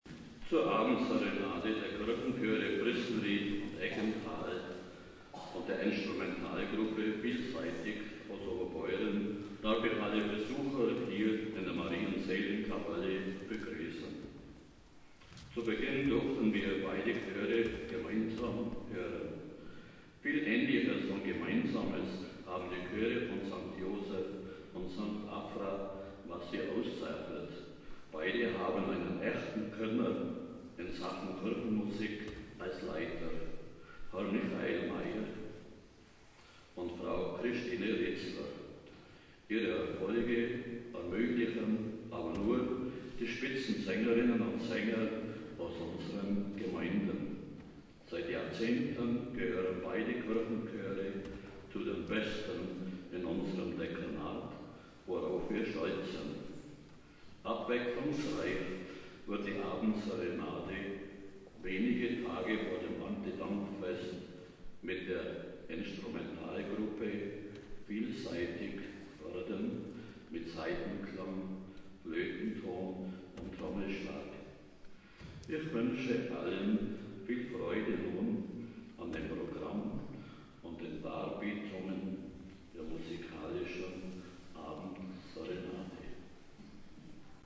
Abendserenade zum Erntedank
Maria-Seelenkapelle Eggenthal
Begrüßung und Einführung